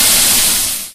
default_cool_lava.1.ogg